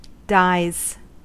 Ääntäminen
Ääntäminen US : IPA : [daɪz] Haettu sana löytyi näillä lähdekielillä: englanti Dies on sanan didie monikko.